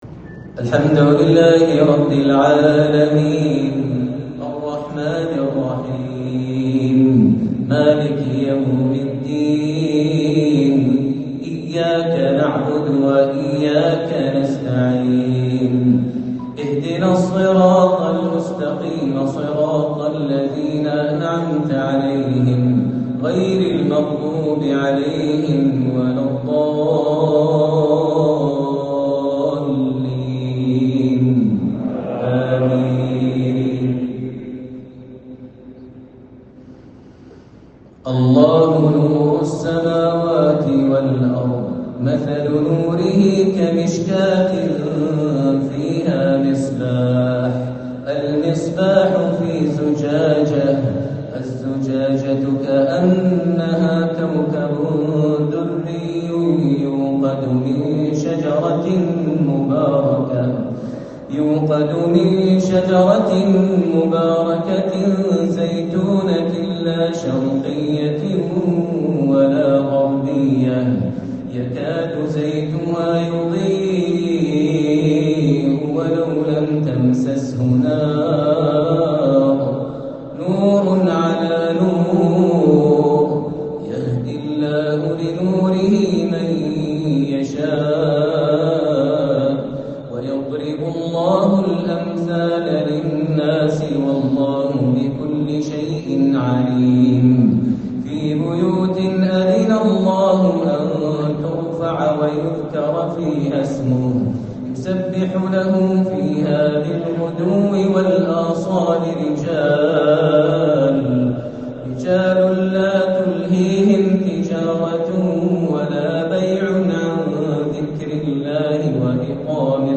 صلاة الشيخ ماهر المعيقلي في افتتاح أحد مساجد مكة المكرمة | عشاء الجمعة 4-8-1444هـ > إمامة الشيخ ماهر المعيقلي وجهوده الدعوية داخل السعودية > المزيد - تلاوات ماهر المعيقلي